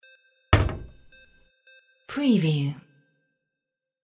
Door cabinet small 03
Stereo sound effect - Wav.16 bit/44.1 KHz and Mp3 128 Kbps
previewFOL_DOOR_CABINET_SMALL_WBHD03.mp3